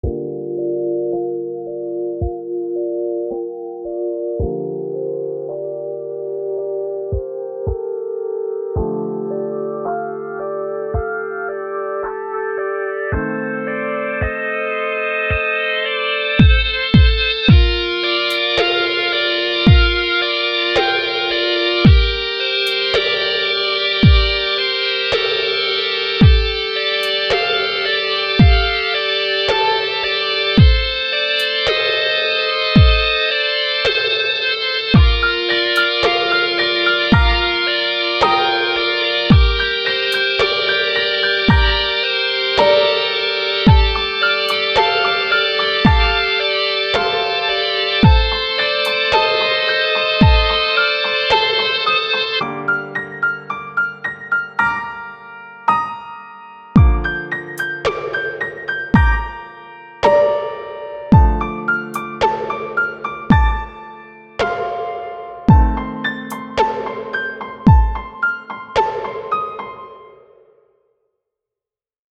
تماما داخل نرم افزار آهنگسازی ساخته شده و صداها الکترونیک هست و از ساز استفاده نشده است